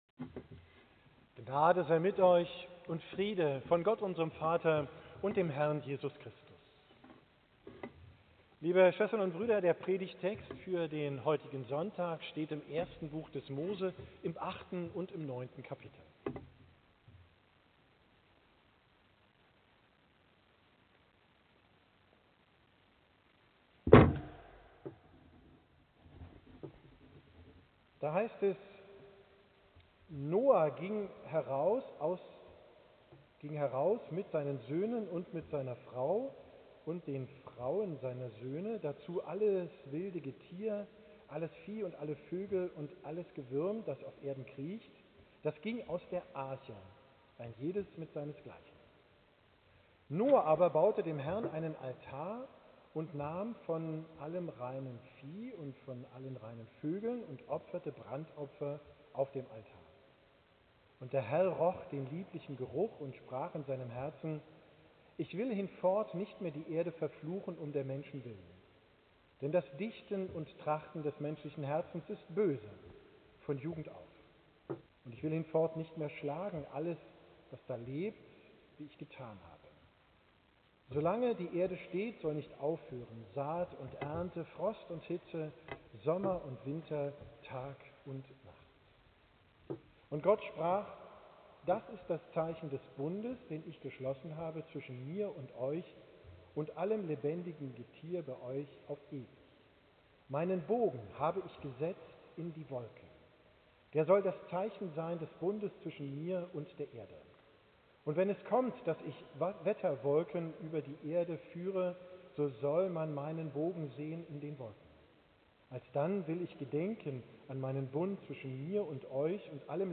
Predigt vom 20.